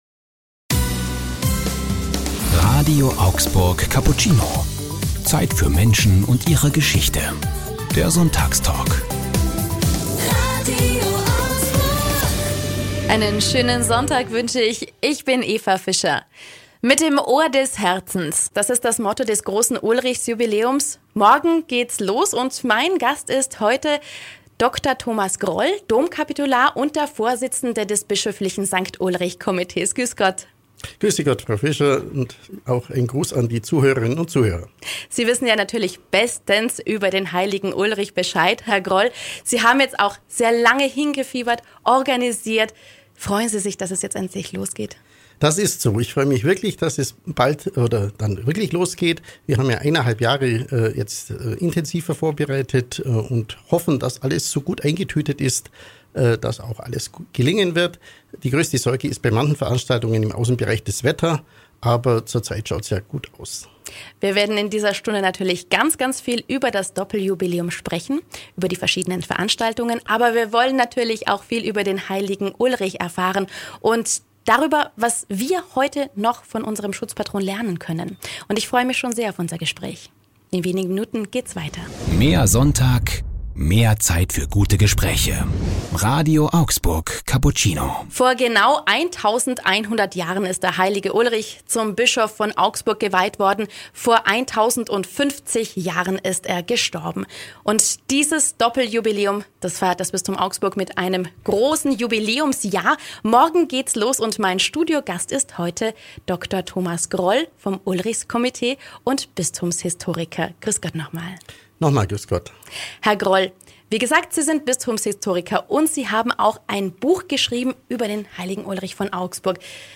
Im Sonntagstalk RADIO AUGSBURG Cappuccino erzählt er spannende Geschichten über den Heiligen Ulrich und wie auch wir alle von ihm lernen können, das Ul-richtige zu tun.